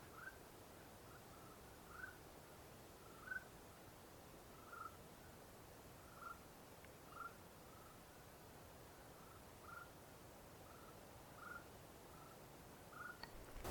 Rufous-tailed Antthrush (Chamaeza ruficauda)
Contactos post playback, al menos dos ejemplares.
Location or protected area: Parque Provincial Caá Yarí
Condition: Wild
Certainty: Observed, Recorded vocal